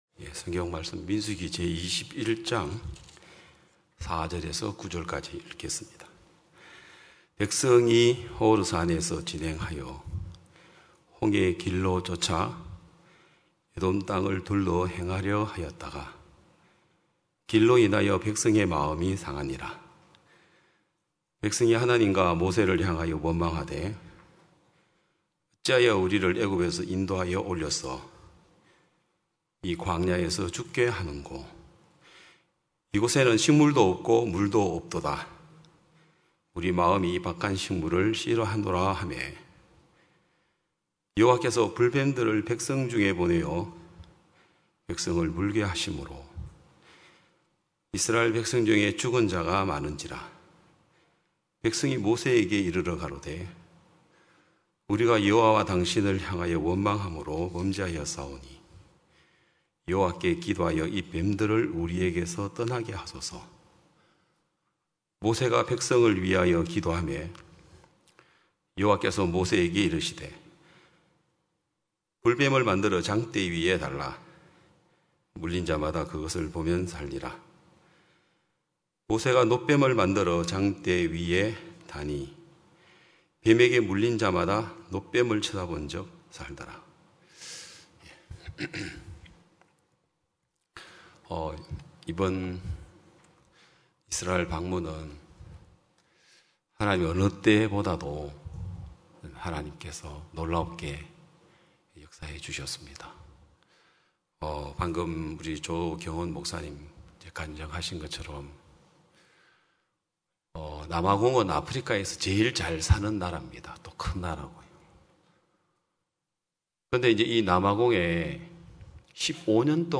2022년 9월 18일 기쁜소식양천교회 주일오전예배